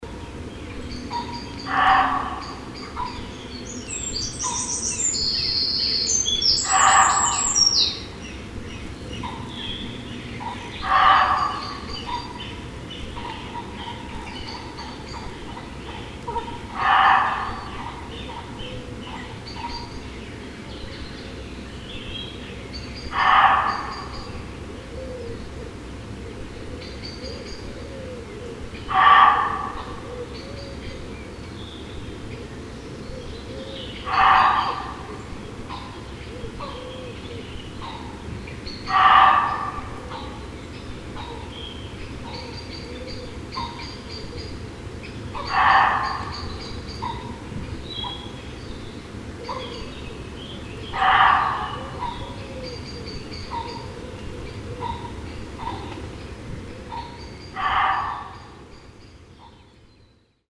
Here are the sounds of the muntjac deer and at this site you will find just about every outdoor sound created in the UK.